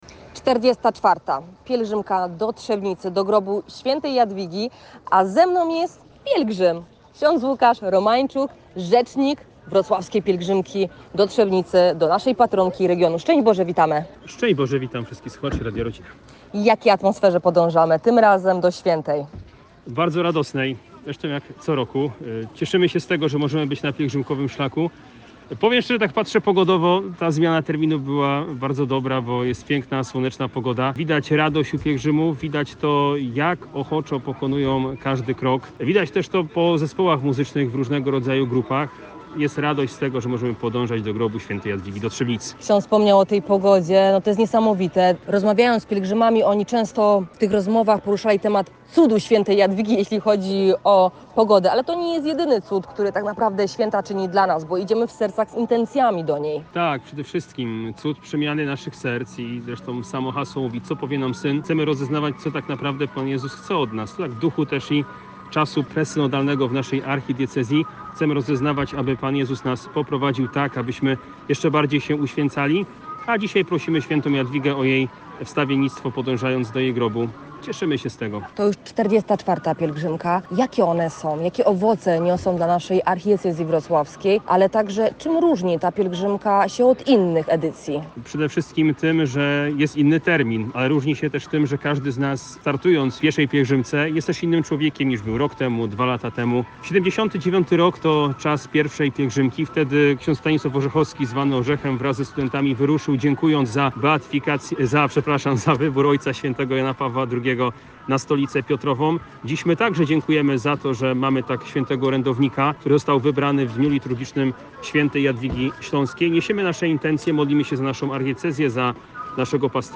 Dziś o godzinie 6:00 spod wrocławskiej katedry wyruszyła 44. pielgrzymka do grobu św. Jadwigi Śląskiej. Specjalnie dla naszych słuchaczy publikujemy relacje z pielgrzymki.